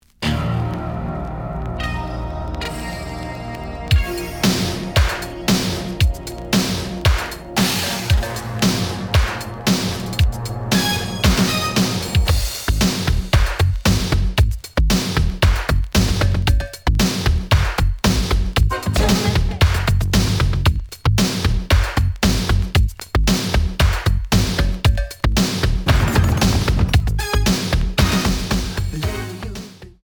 The audio sample is recorded from the actual item.
●Genre: Funk, 80's / 90's Funk
Slight edge warp.